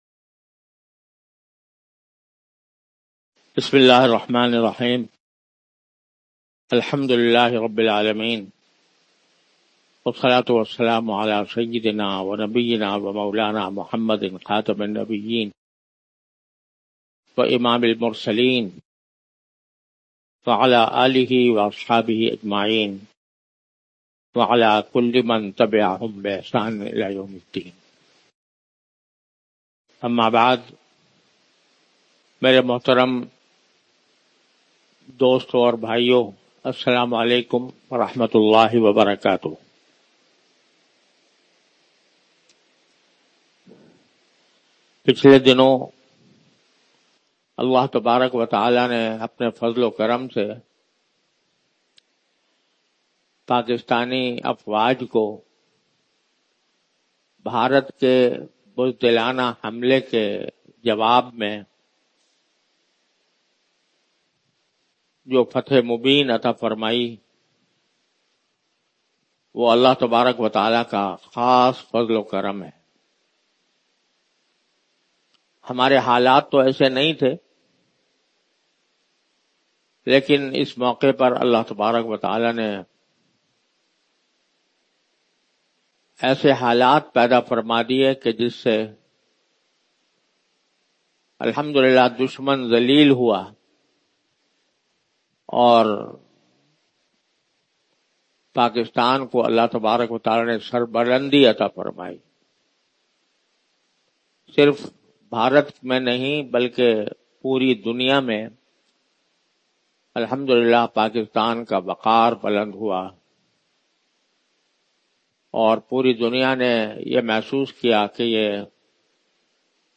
Audio Bayan Mufti Muhammad Taqi Usmani